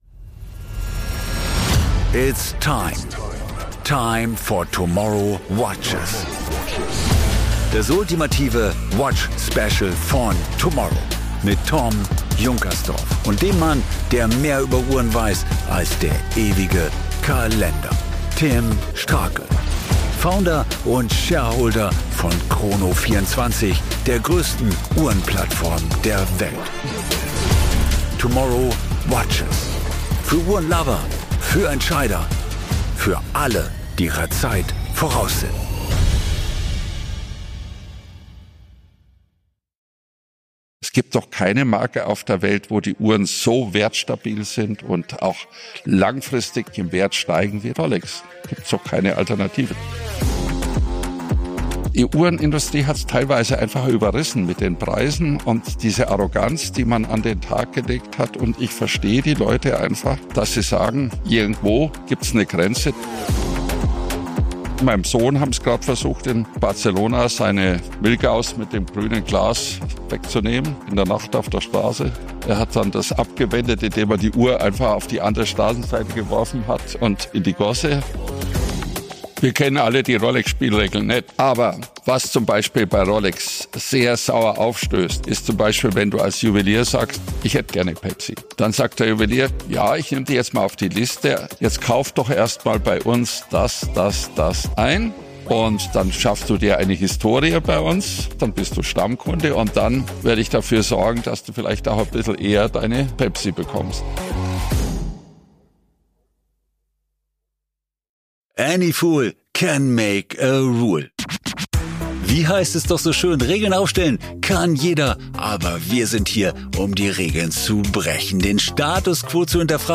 Jetzt in TOMorrow und im TOMorrow Videopodcast auf YouTube live aus dem Koenigshof in München.